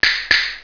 Electric hit #1
SNAP.wav